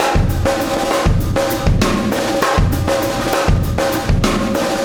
Extra Terrestrial Beat 19.wav